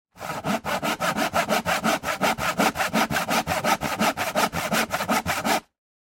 На этой странице собраны звуки ножовки в разных вариациях: от плавных движений по дереву до резких рывков при работе с металлом.
Ножовка - Альтернативный вариант